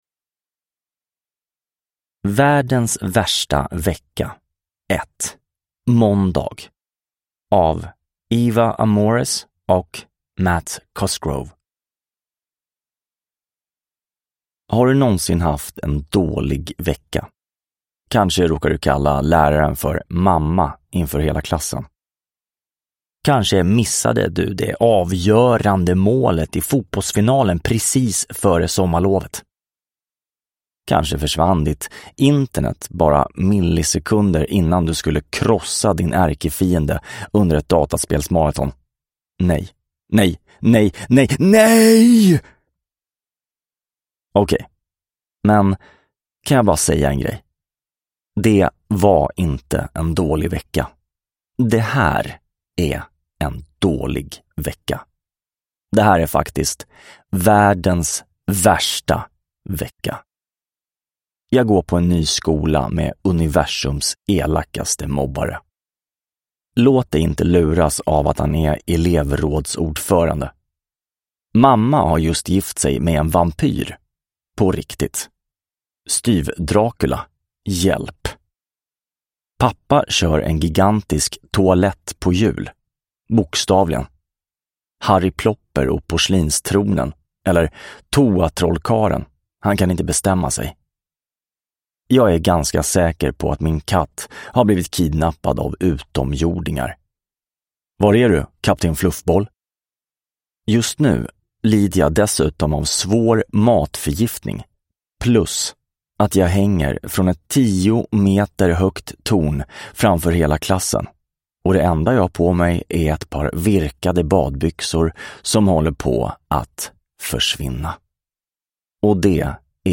Världens värsta vecka. Måndag – Ljudbok